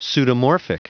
Prononciation du mot pseudomorphic en anglais (fichier audio)
Prononciation du mot : pseudomorphic